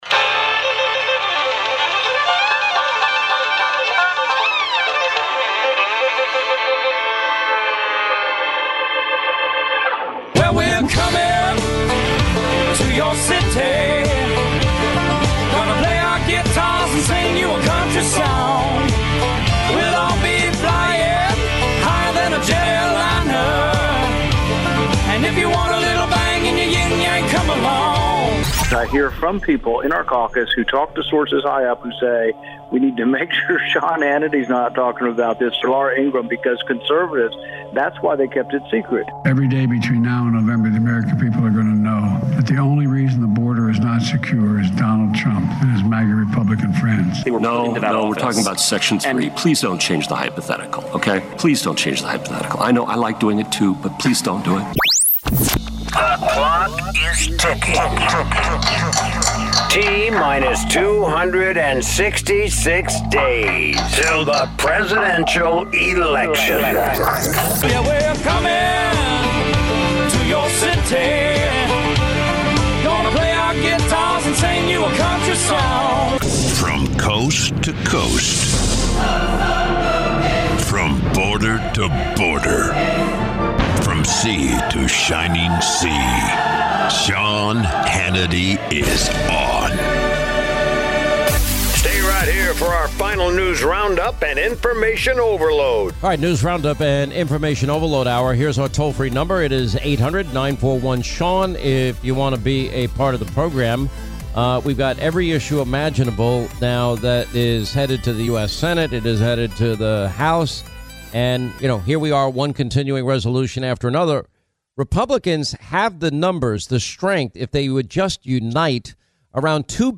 Florida Senator Marco Rubio spoke with Sean Hannity on Monday on The Sean Hannity radio show about the two biggest issues facing the country: the economy and the border crisis.